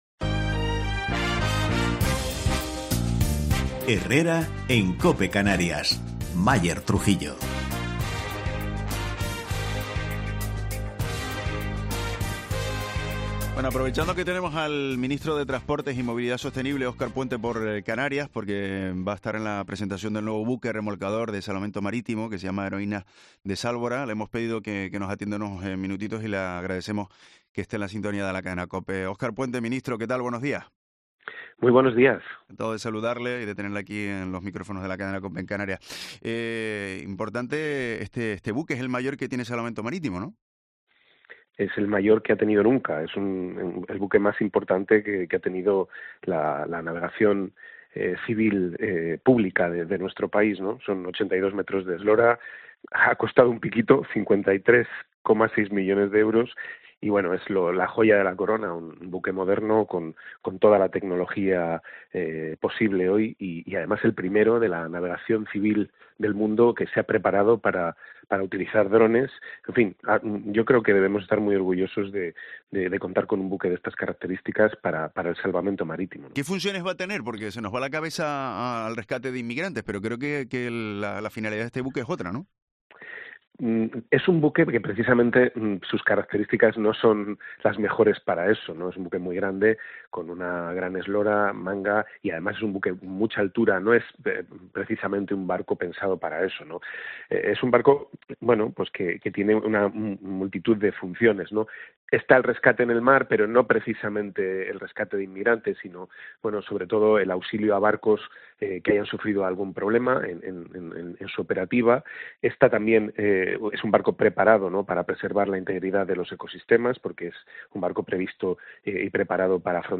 Entrevista a Óscar Puente, ministro de Transportes, en Herrera en COPE Canarias